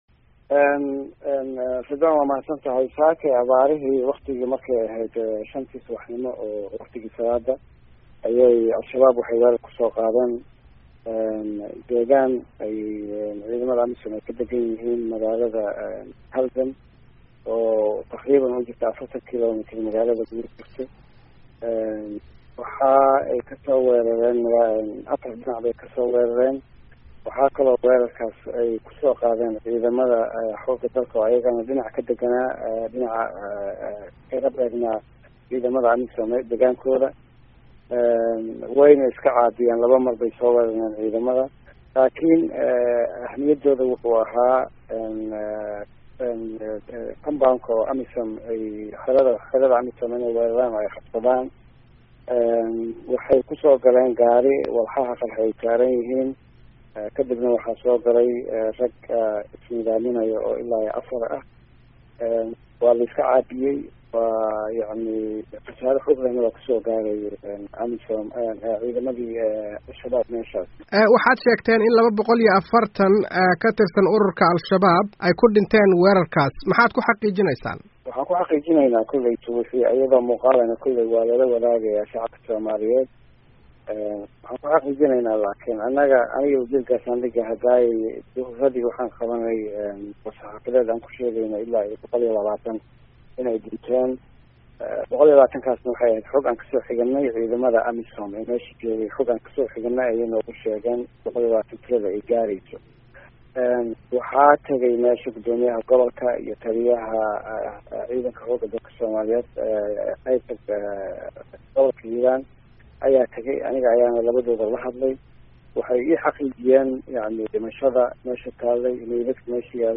Wareysi: Wasiirka Amniga Somalia